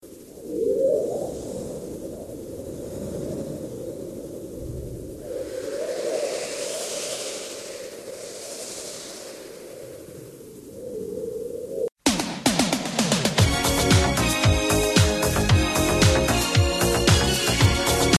Not earrape tumbleweed sound effect.ogg
Not_earrape_tumbleweed_sound_effect.ogg